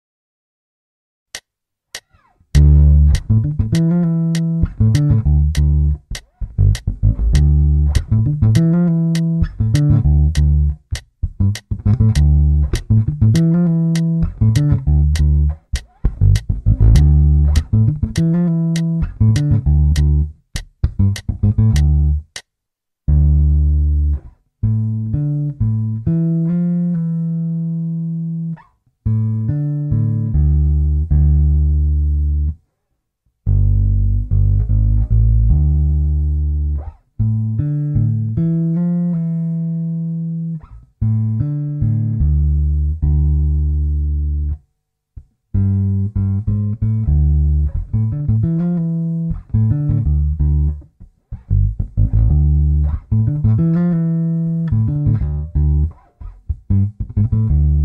L06 D minor groovy bass line
A groovy D minor bassline based on a root/fifth/octave pattern with a slide to the 9th (E) and back to the octave.
The chromatic pickup in bar 2 and 4 is a typical soulfunk pattern.
L06Dminorbassline.mp3